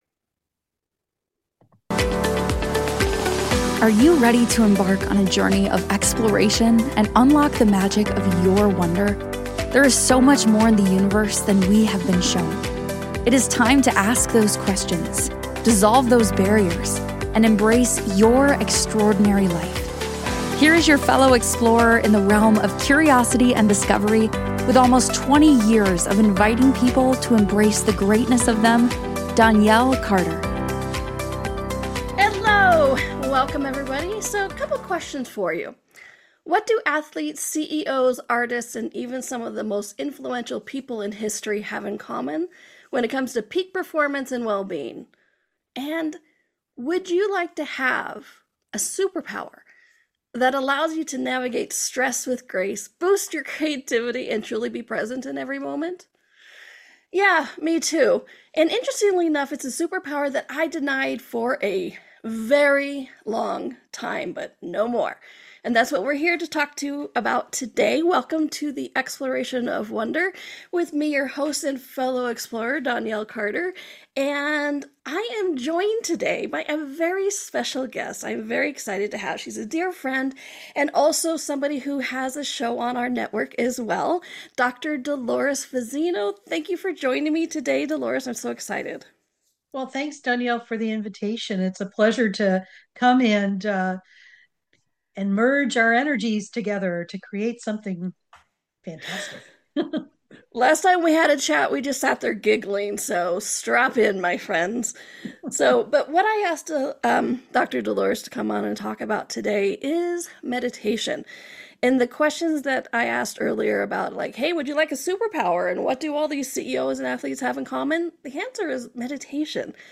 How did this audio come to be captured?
LIVE MONDAY'S 4 PM ET/3CT/2MT/1PT